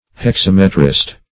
Search Result for " hexametrist" : The Collaborative International Dictionary of English v.0.48: Hexametrist \Hex*am"e*trist\, n. One who writes in hexameters.